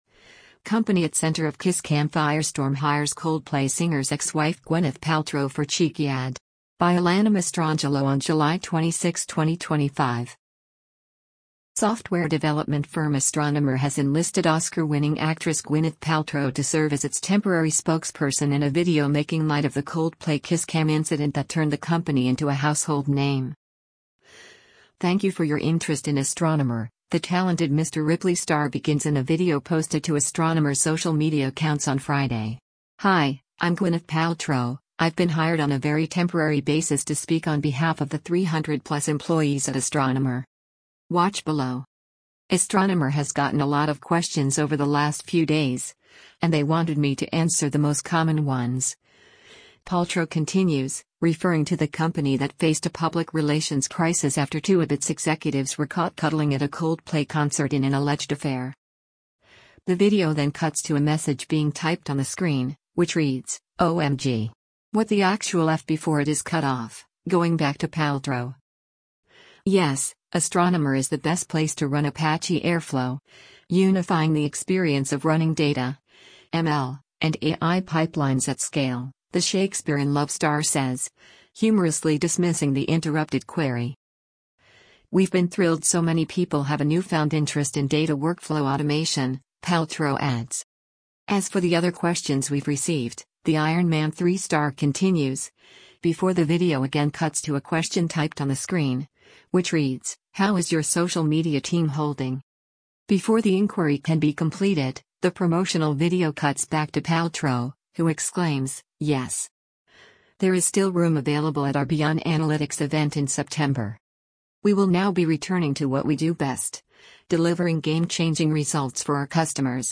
Software development firm Astronomer has enlisted Oscar-winning actress Gwyneth Paltrow to serve as its temporary spokesperson in a video making light of the Coldplay kiss cam incident that turned the company into a household name.